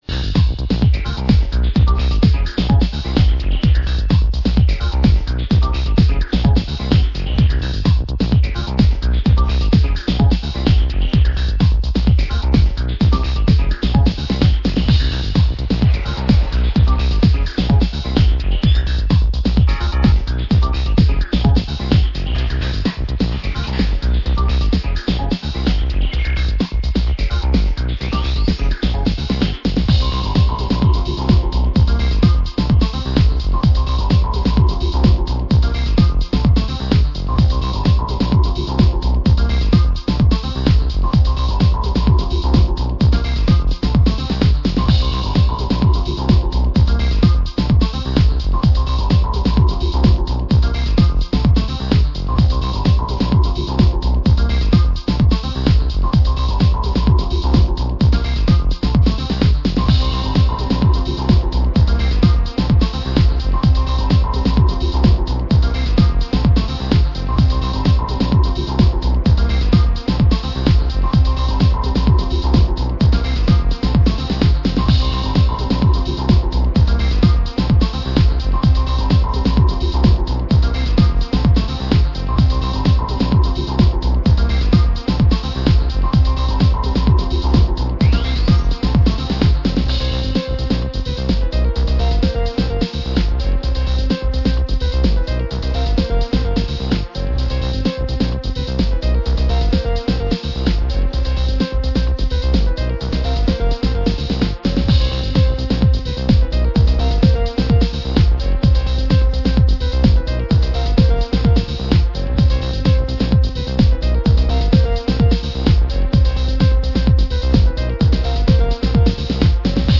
techno, electro, new wave driving music